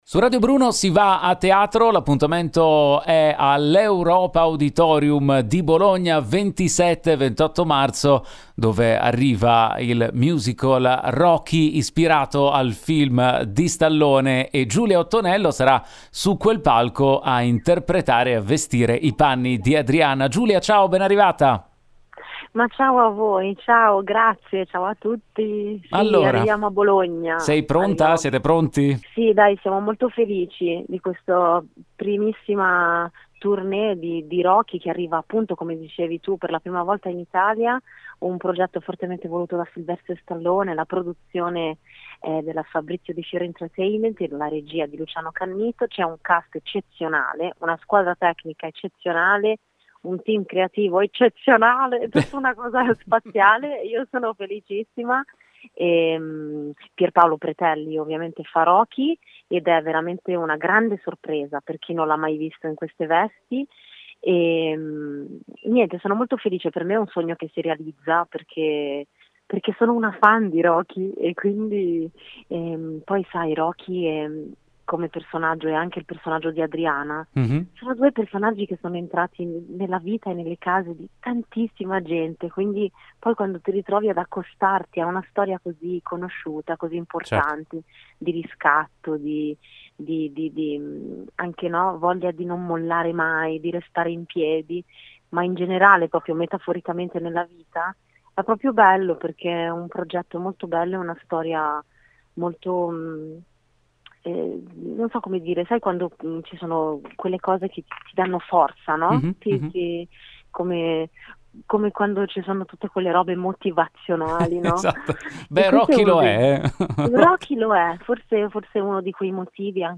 Home Magazine Interviste Rocky torna sul ring al Teatro EuropAuditorium di Bologna